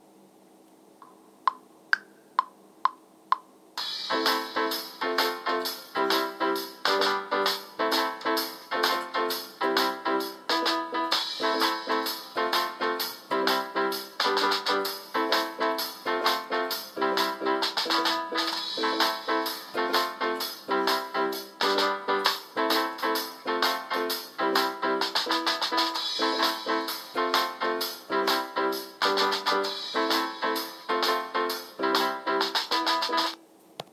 Lonely piano with backing 1